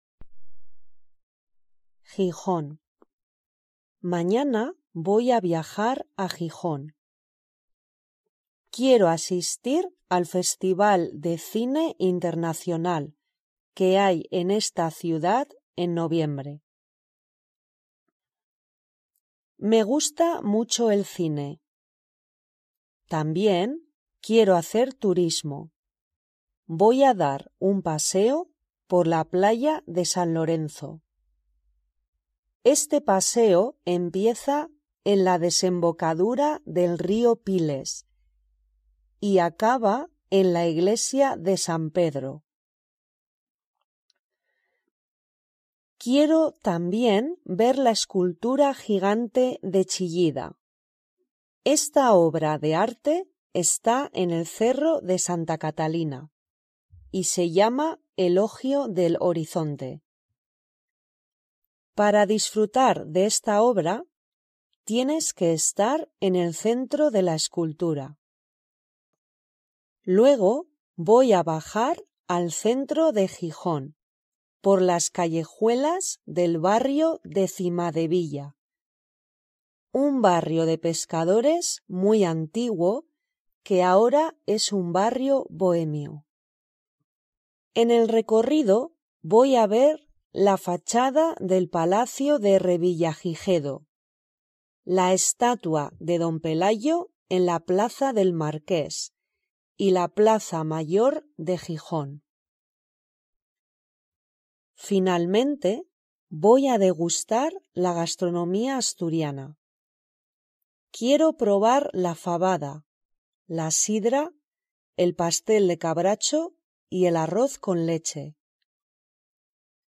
Spanish online reading and listening practice – level A1
gijon-reader.mp3